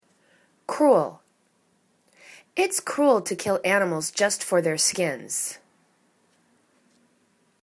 cru.el /'kru:әl/ adj